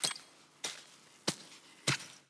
Babushka / audio / sfx / Farming / SFX_Hacke_01_Reverb.wav
SFX_Hacke_01_Reverb.wav